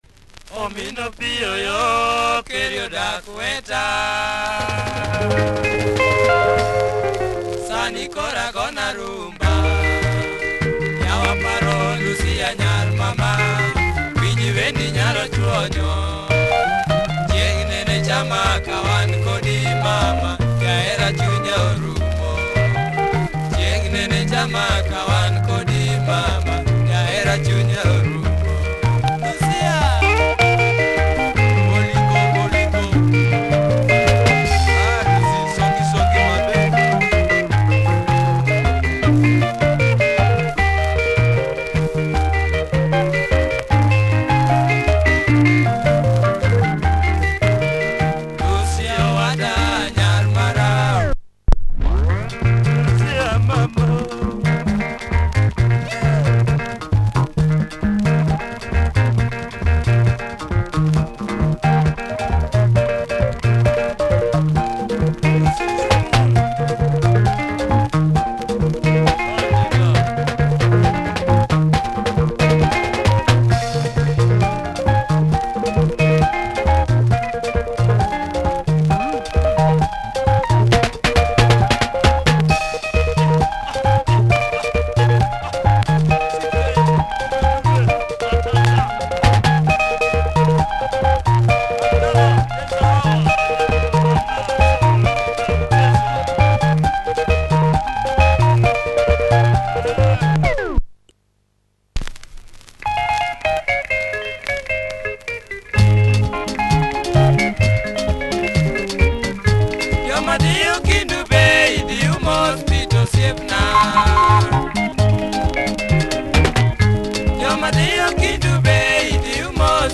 Nice luo benga by this prolific group.